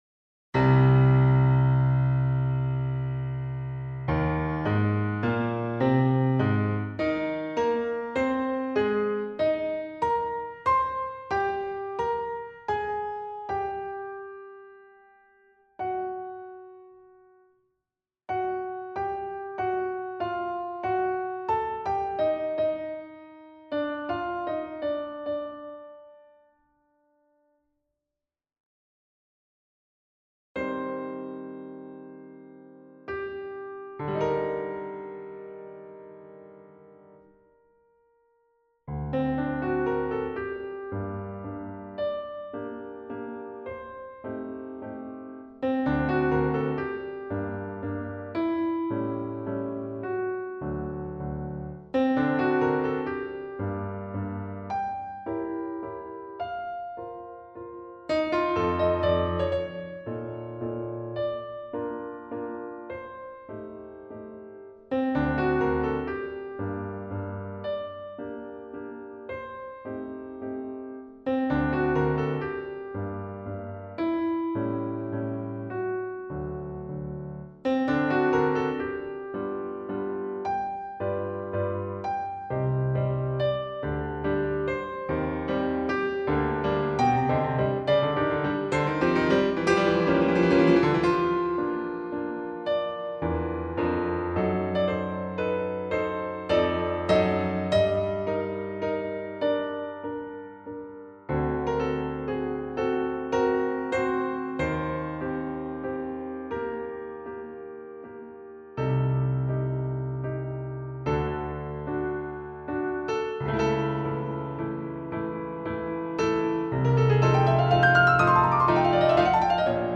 ノクターン　NOCTURNE
ノクターンとは、「夜想曲」と訳されるとおり、夜の静けさの中でうっとりと聴くには凄く素敵な空間を演出してくれるでしょう。